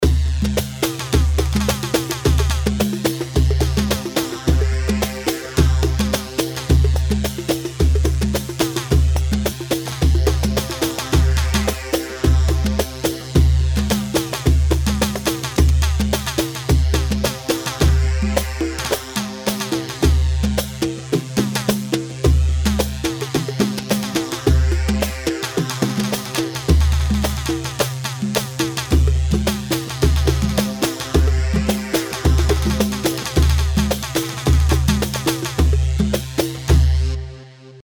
Hewa 4/4 108 هيوا
Hewa-108-mix.mp3